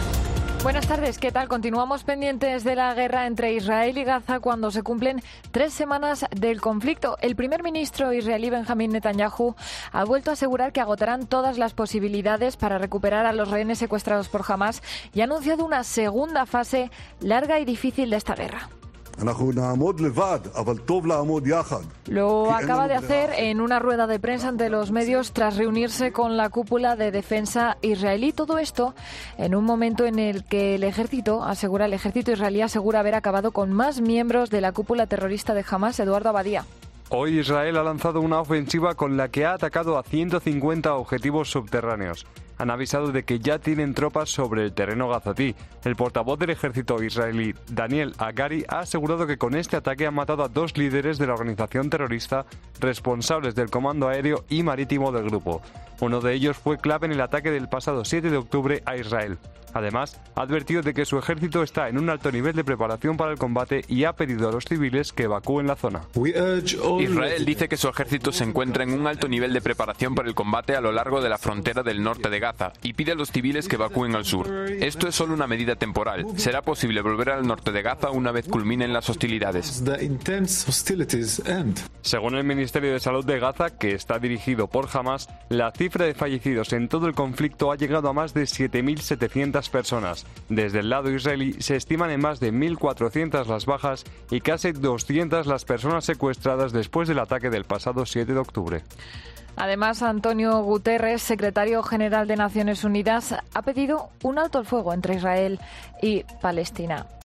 Lo ha afirmado en una rueda de prensa ante los medios, en la que también ha asegurado que "agotarán las posibilidades" para liberar a los rehenes